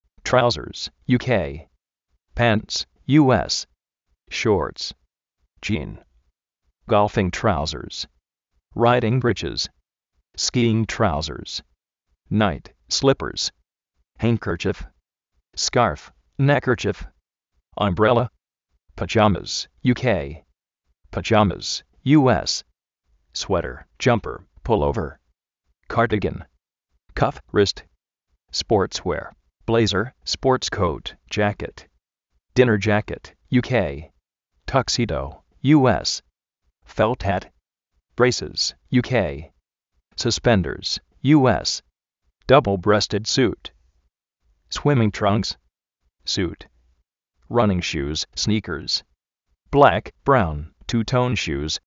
Vocabulario en ingles, diccionarios de ingles sonoros, con sonido, parlantes, curso de ingles gratis
óver(kóut)
tu-pí:s sút